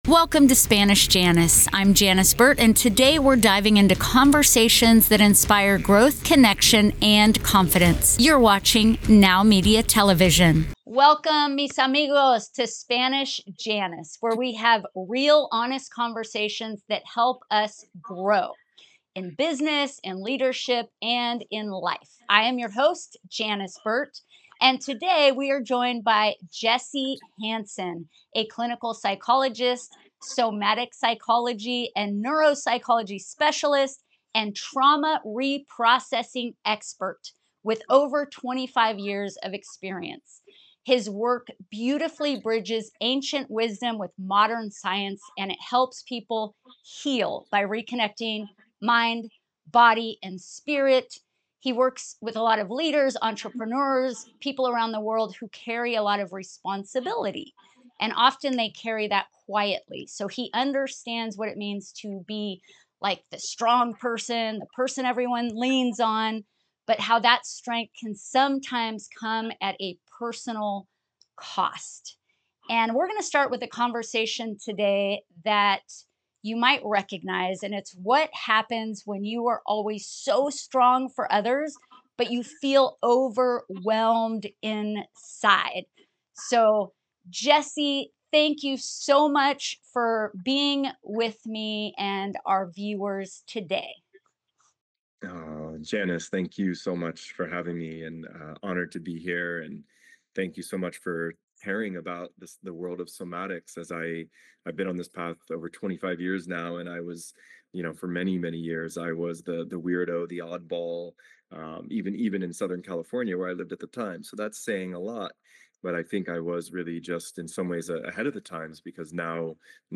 Together, they explore what happens when you are always the strong one for others, yet feel overwhelmed inside. This honest conversation dives into conscious leadership, boundaries, nervous system regulation, trauma healing, somatic breathwork, hypnotherapy, and the deep connection between mind, body, and spirit.